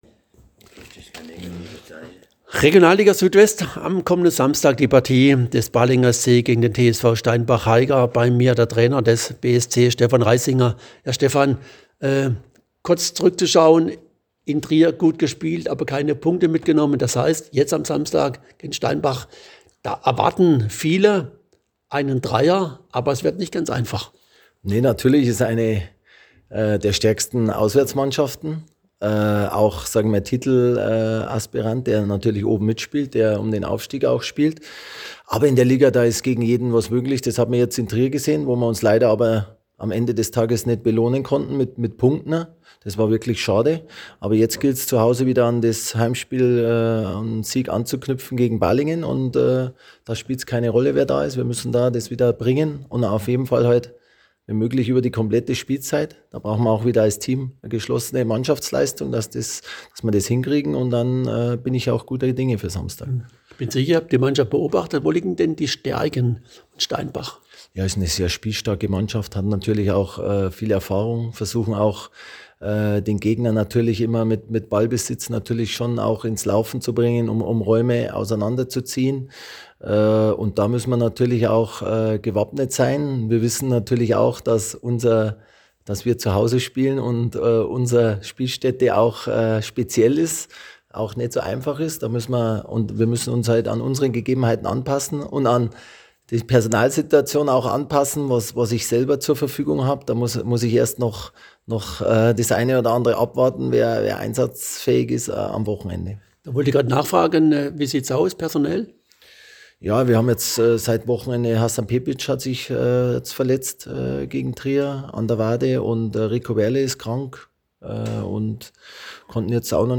Spieltagsinterview